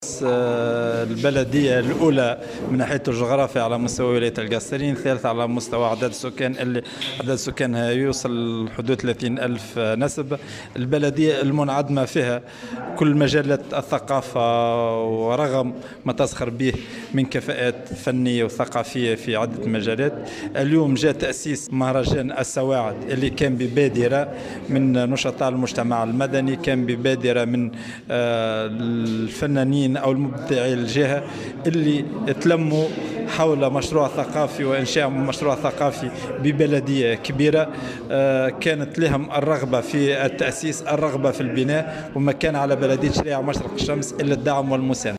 رئيس بلدية الشرايع مشرق الشمس